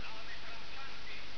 Type: Sound Effect